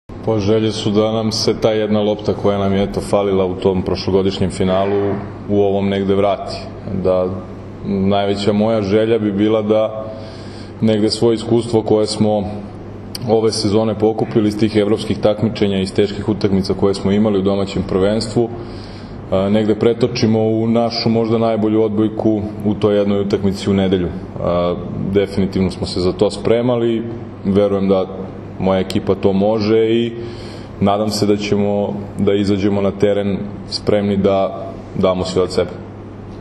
U beogradskom hotelu “M” danas je održana konferencija za novinare povodom finalnih utakmica 59. Kupa Srbije 2024/2025. u konkurenciji odbojkašica i jubilarnog, 60. Kupa Srbije 2024/2025. u konkurenciji odbojkaša, koje će se odigrati se u “MTS hali Jezero” u Kragujevcu u nedelju, 23. februara.
Izjava